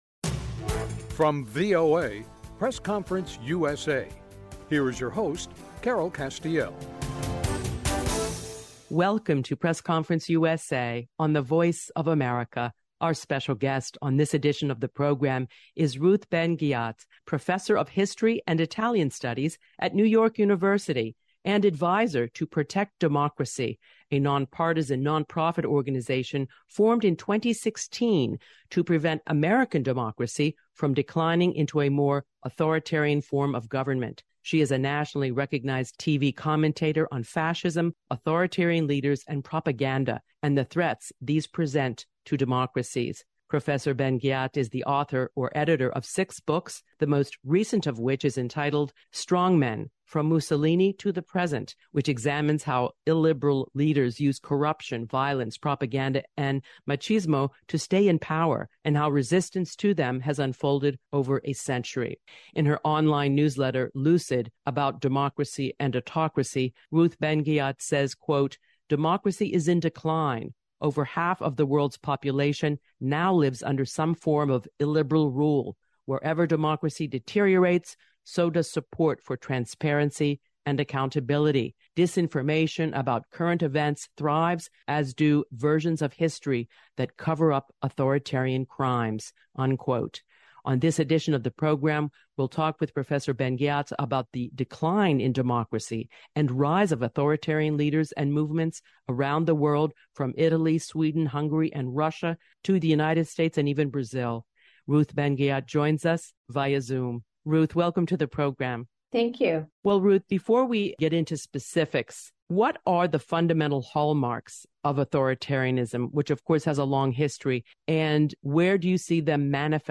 A Conversation with Ruth Ben Ghiat about the threat of Authoritarianism